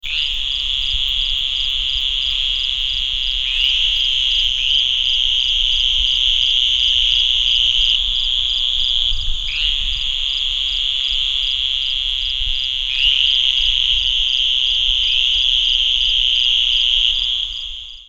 Advertisement Calls
Sound  This is an 18 second recording of an advertisement chorus of a group of Western Green Toads calling at night from a small pond in the grasslands of Cochise County, Arizona in August.
bdebiliscall.mp3